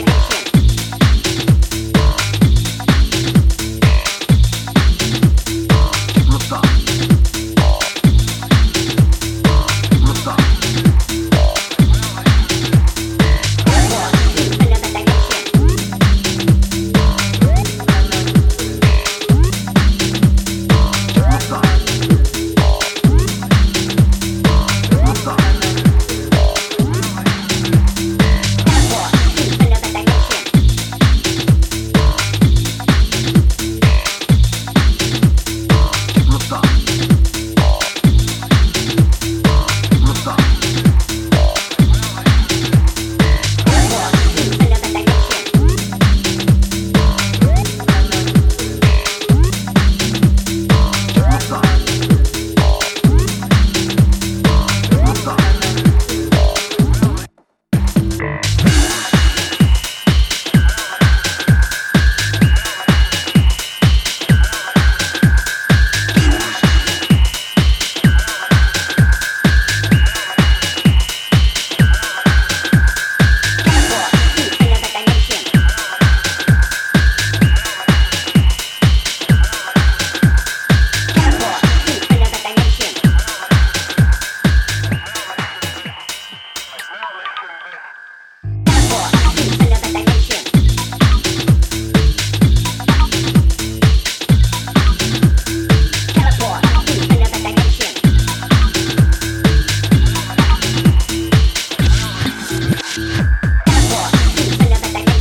is a sonic cheese plate for the dedicated dancer.